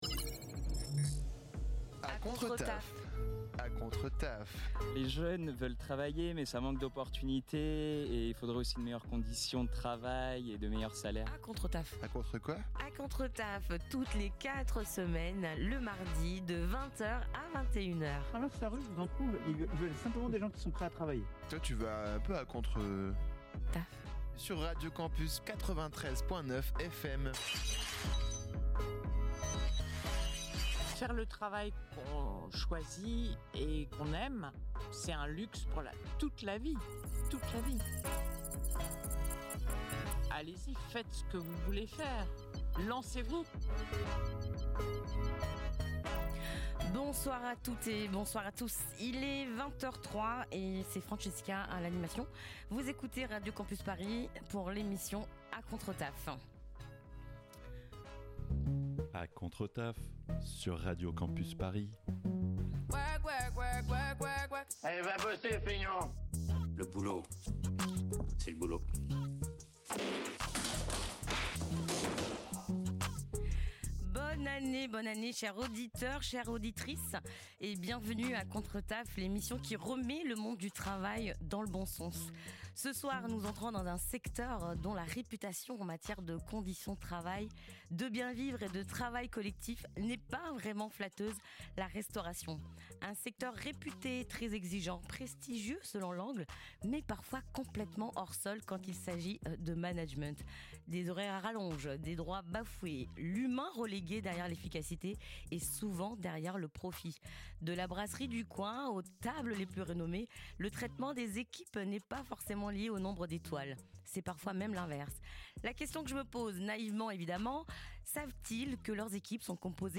Type Magazine Société
(Des témoignages de salariés: des faits, uniquement des faits!)